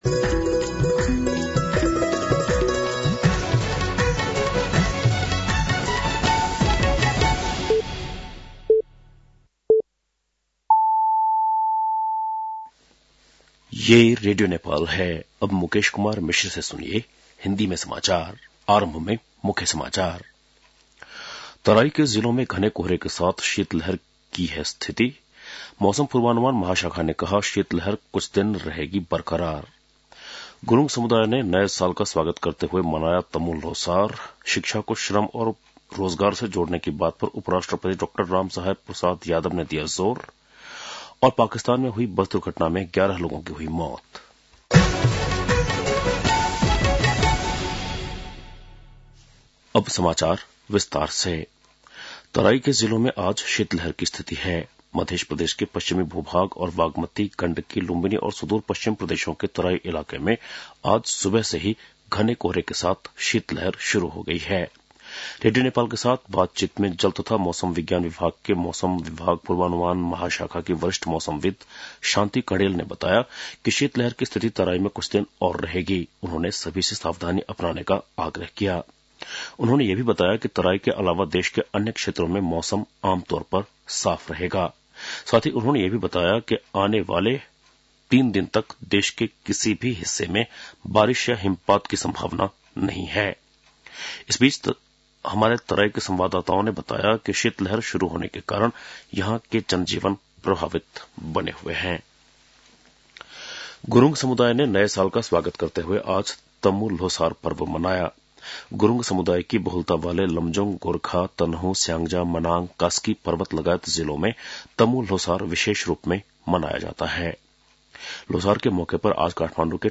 बेलुकी १० बजेको हिन्दी समाचार : १६ पुष , २०८१
10-PM-Hindi-NEWS-9-15.mp3